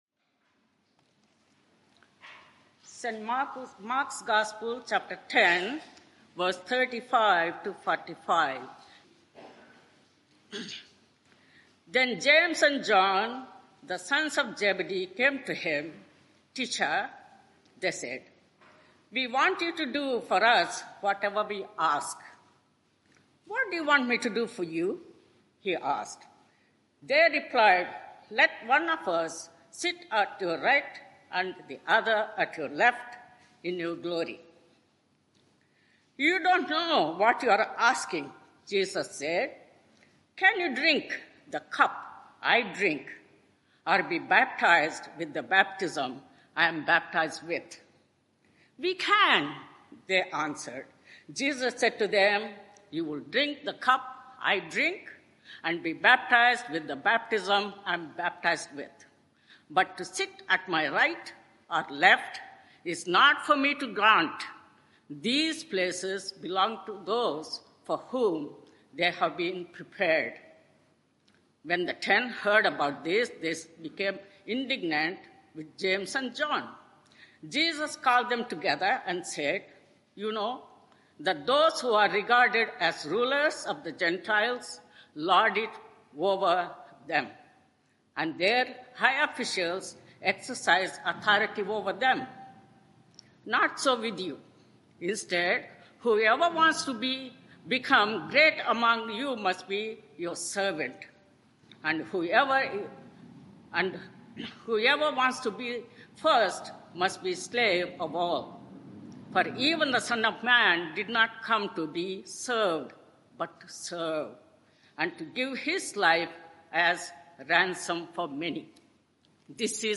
Media for 11am Service on Sun 05th May 2024 11:00 Speaker
Sermon